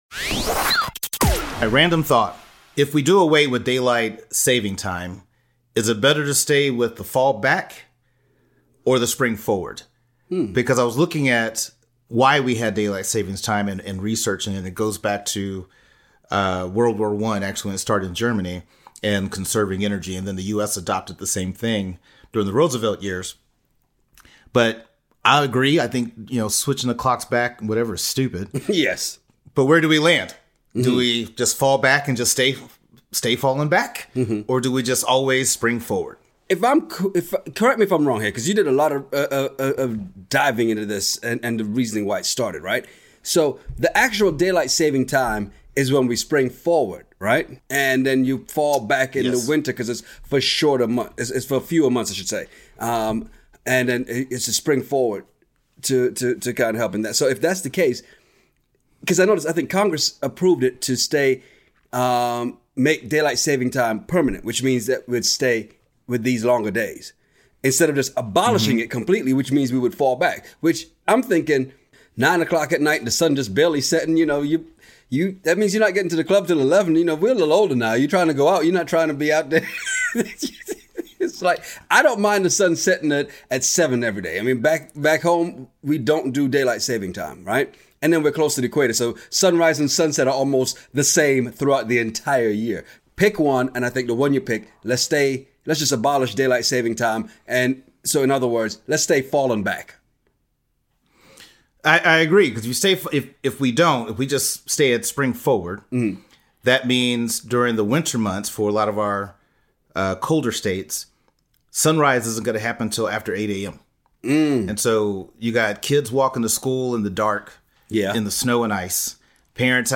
Well we are those guys and we have been having these conversations since college.